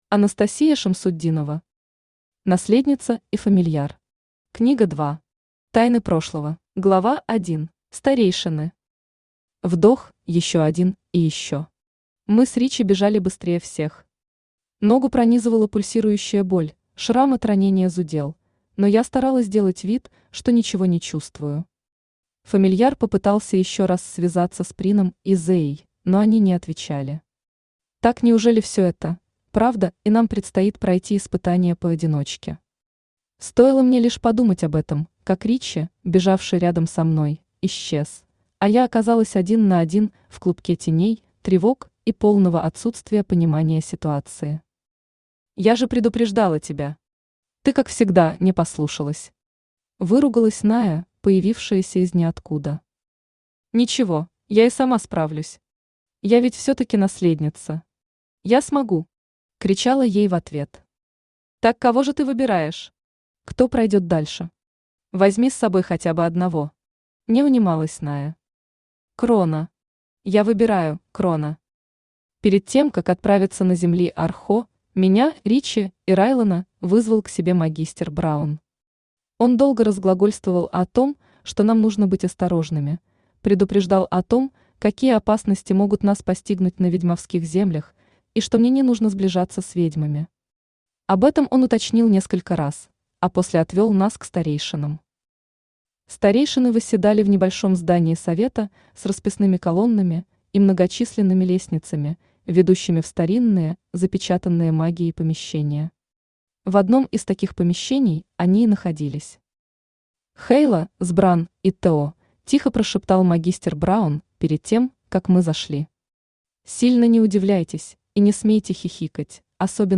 Тайны прошлого Автор Анастасия Рауфовна Шамсутдинова Читает аудиокнигу Авточтец ЛитРес.